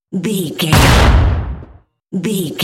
Dramatic hit explosion
Sound Effects
heavy
intense
dark
aggressive